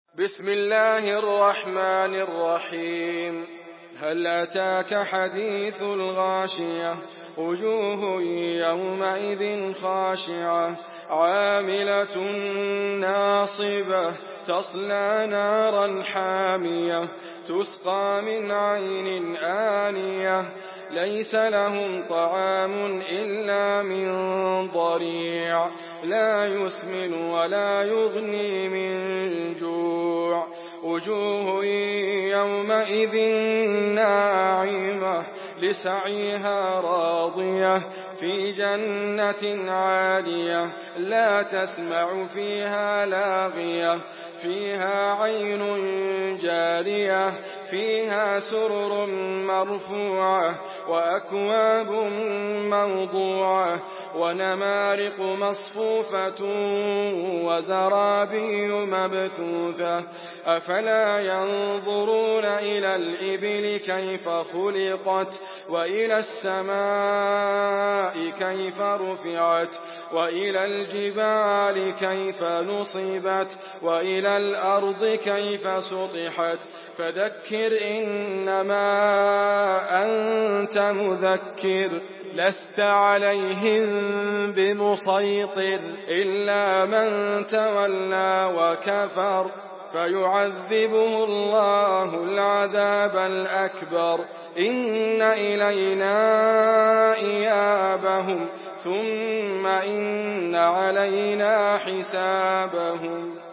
سورة الغاشية MP3 بصوت إدريس أبكر برواية حفص عن عاصم، استمع وحمّل التلاوة كاملة بصيغة MP3 عبر روابط مباشرة وسريعة على الجوال، مع إمكانية التحميل بجودات متعددة.
مرتل